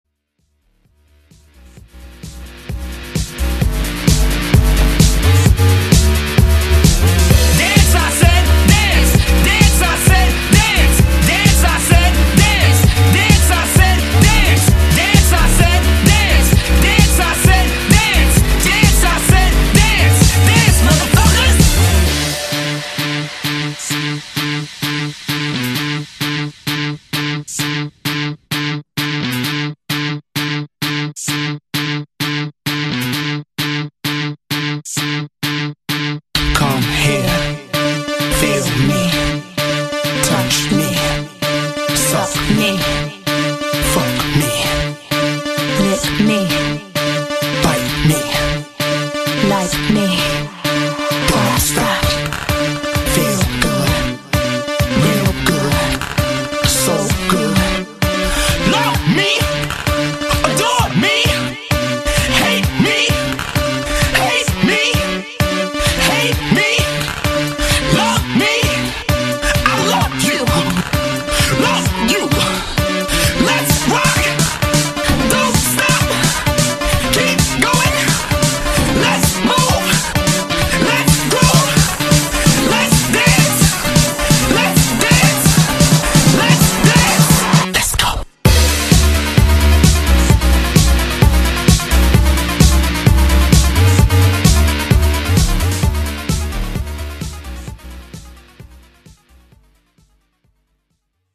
Жанр: Club • Dance • DJs